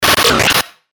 FX-1396-BREAKER
FX-1396-BREAKER.mp3